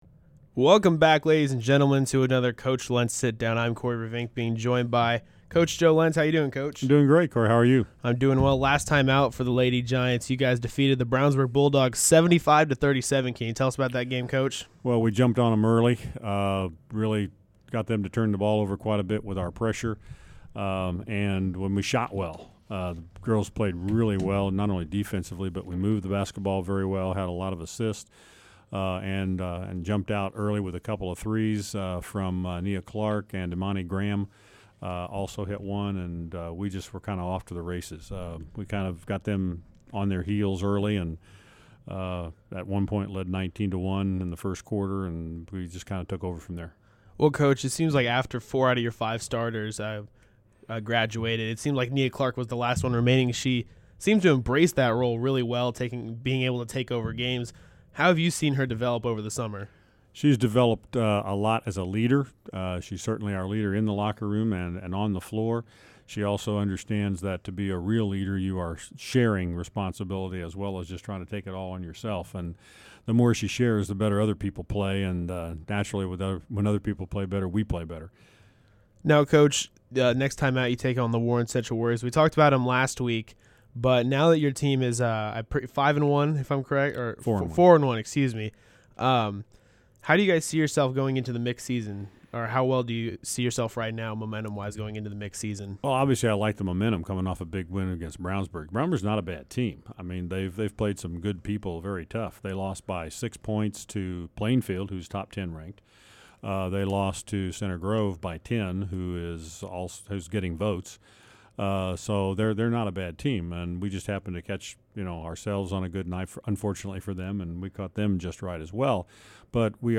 a stand alone interview